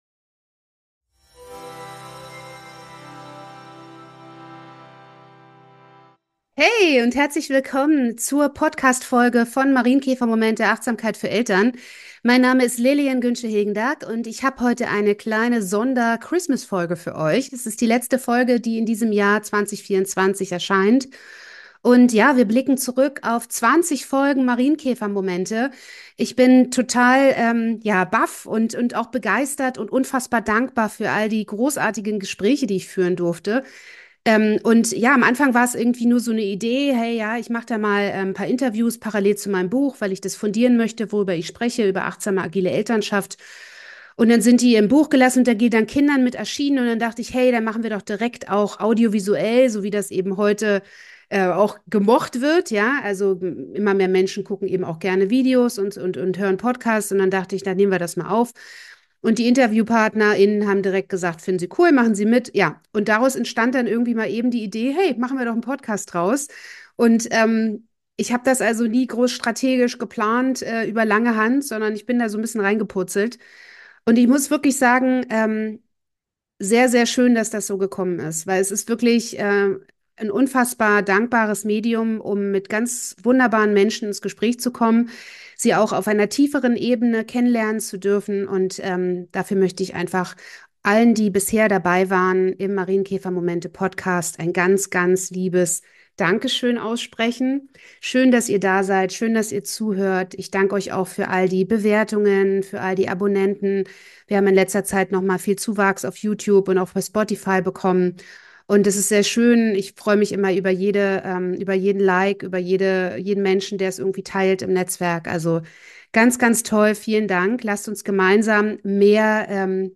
Die Folge ist ein Christmas-Special, das Achtsamkeit, Humor, Musik und praktische Tipps für die Weihnachtszeit und den Jahreswechsel vereint. Ein Mix aus Besinnlichkeit, Leichtigkeit und wertvollen Impulsen.